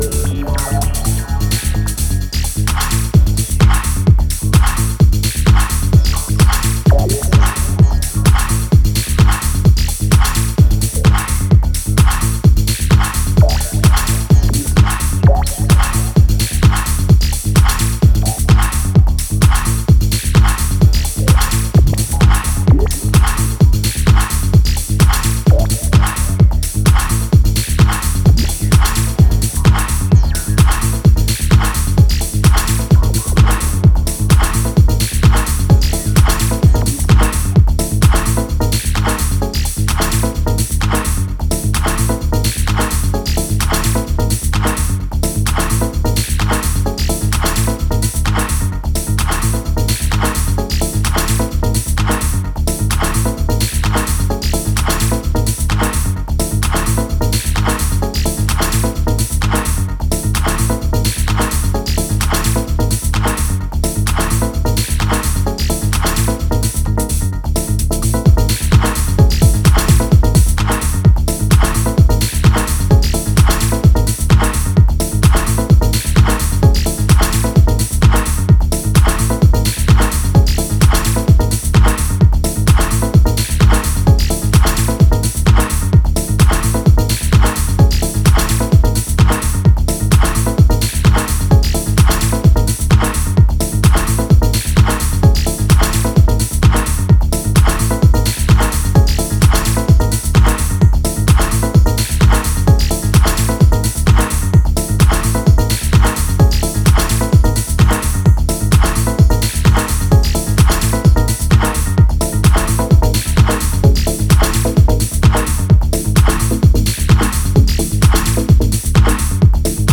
We are pleased to present our 6 vinyl EP
combining deep beats with hypnotic grooves.